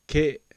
Ke - short vowel sound | 495_14,400